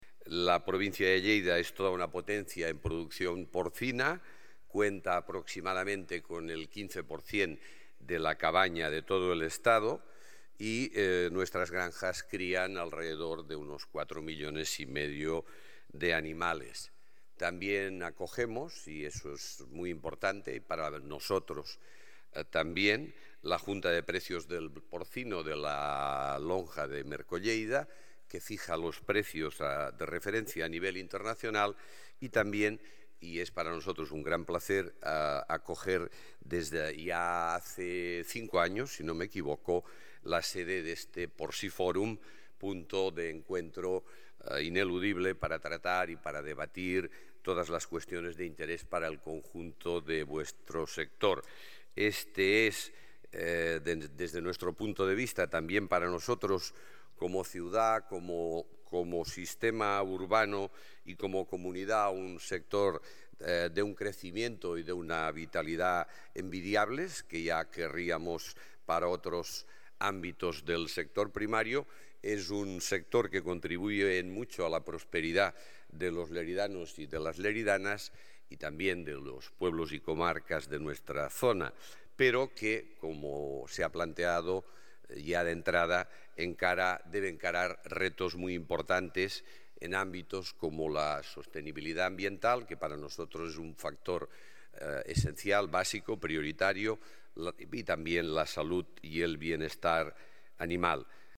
tall-de-veu-de-lalcalde-miquel-pueyo-sobre-porciforum-a-la-llotja